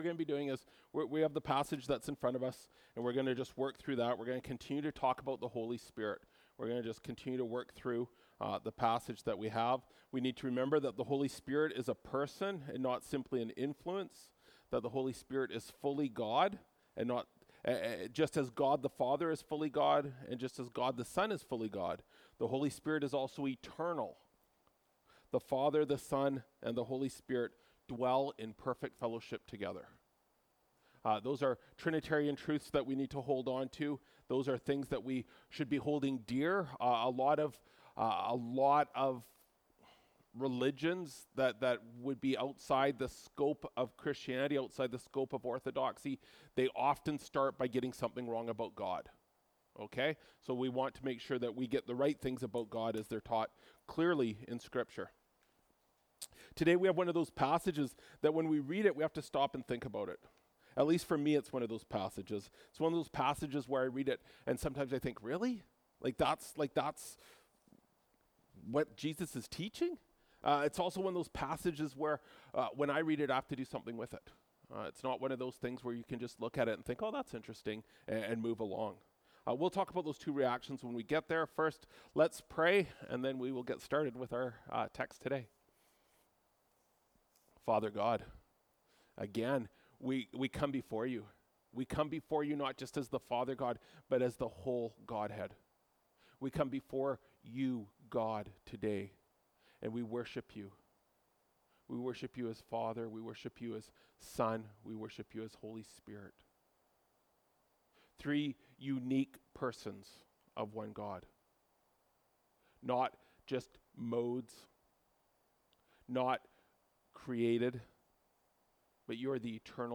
Mar 10, 2024 Trinitarian Transitions (John 16:4b-15) MP3 SUBSCRIBE on iTunes(Podcast) Notes Discussion Sermons in this Series This sermon was preached and recorded at Grace Church - Salmon Arm.